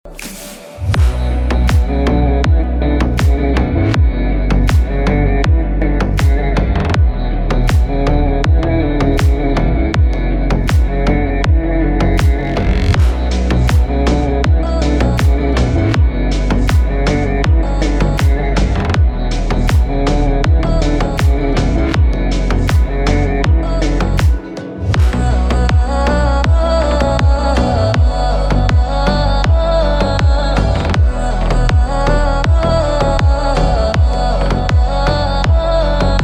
drill rap song